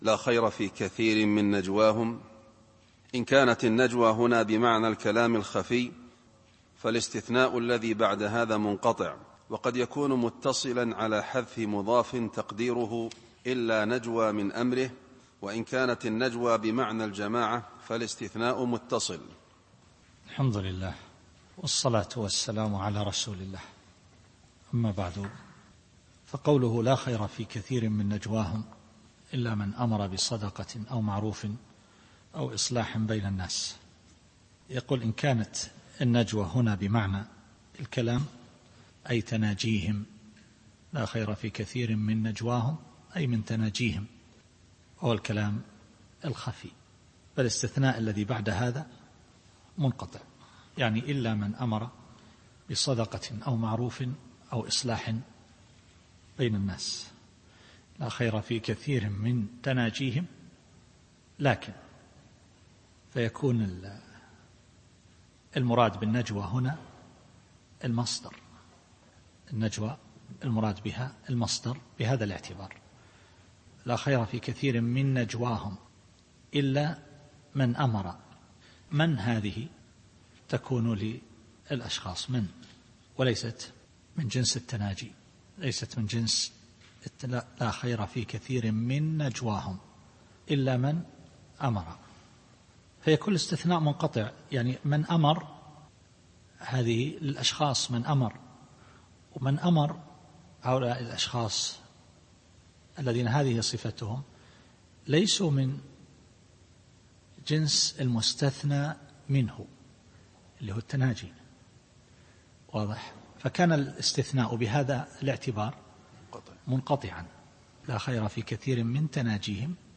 التفسير الصوتي [النساء / 114]